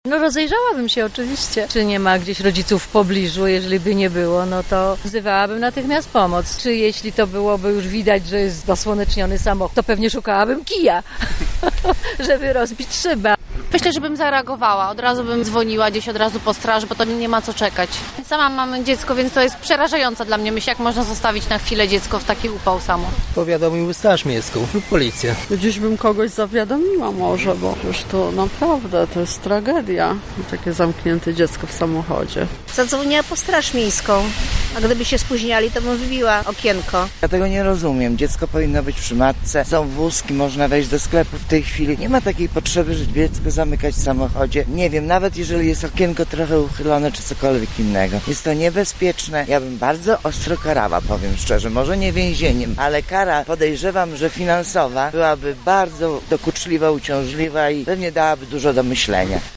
Spytaliśmy lublinian, jak zachowaliby się, gdyby mieli do czynienia z taką sytuacją.
lublinianie
sonda.mp3